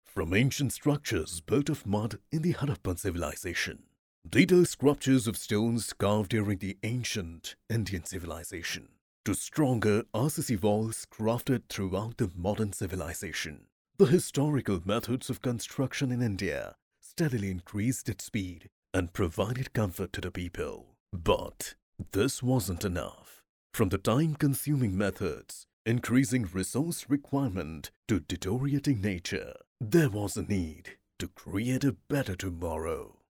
Male
Hindi (Native) , British English
Authoritative, Bright, Bubbly, Corporate, Deep, Engaging, Natural, Soft, Versatile
Indian English
Voice reels
Microphone: Rode NT 1 + Audio Technica + AKG P120
Audio equipment: decibel tested acoustically treated room + AI Uphoria Bheringer + JBL K4 Nano Monitors